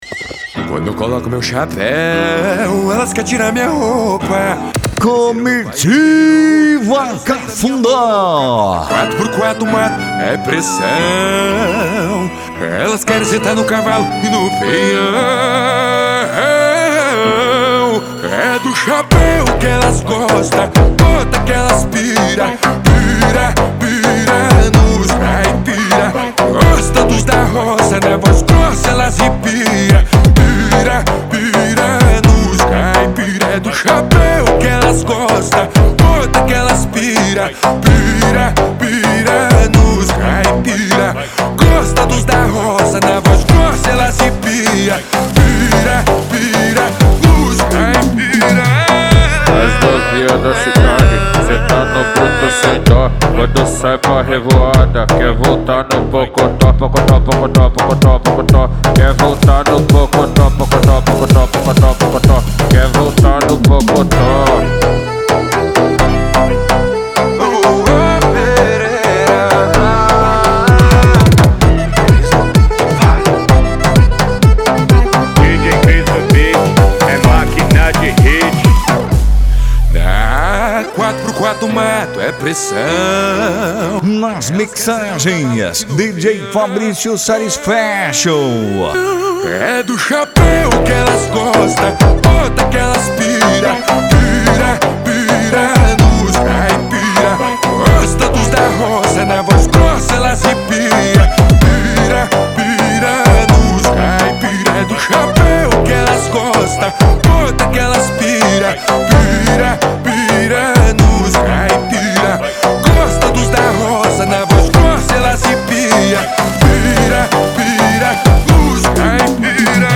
Country Music
Funk
Funk Nejo
SERTANEJO